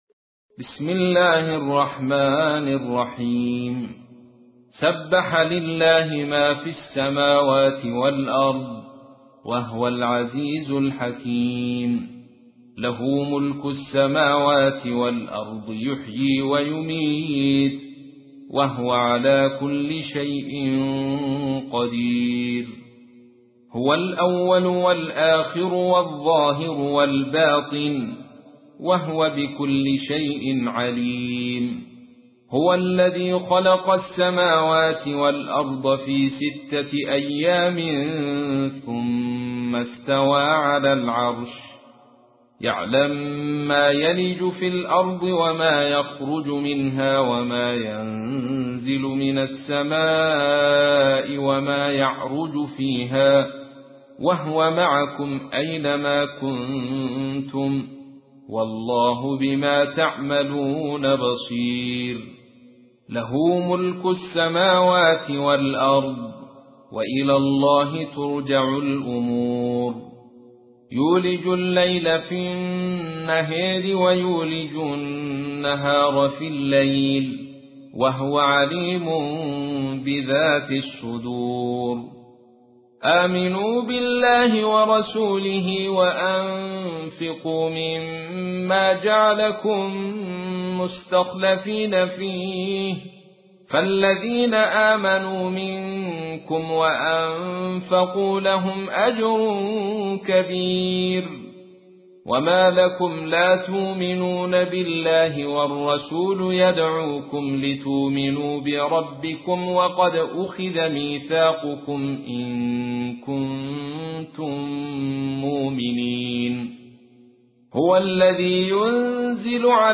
تحميل : 57. سورة الحديد / القارئ عبد الرشيد صوفي / القرآن الكريم / موقع يا حسين